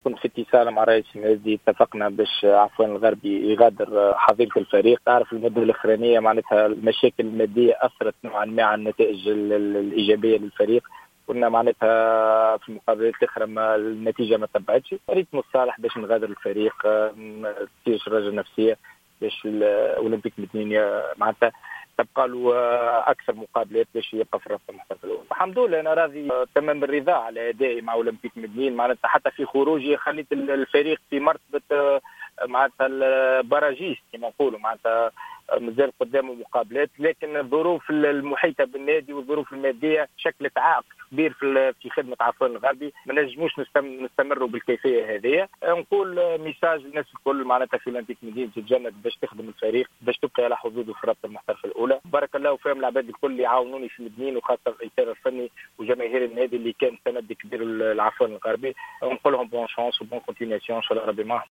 تصريح لإذاعة الجوهرة أف أم